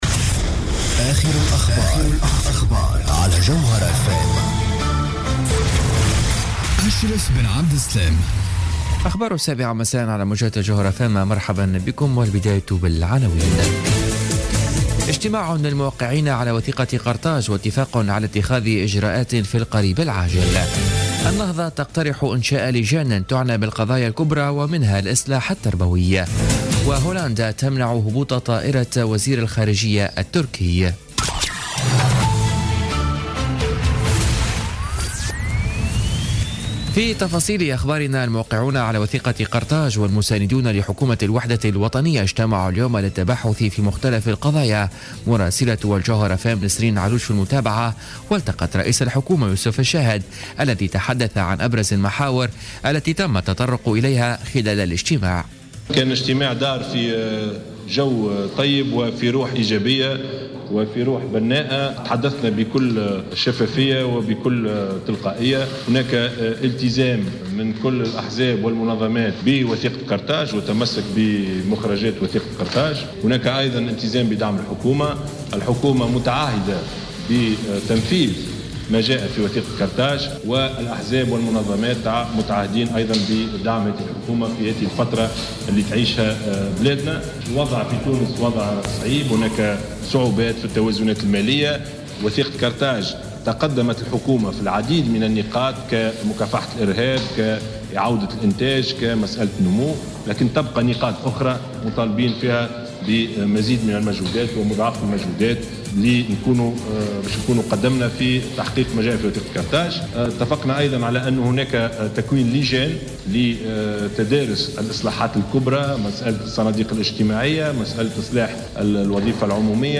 نشرة أخبار السابعة مساء ليوم السبت 11 مارس 2017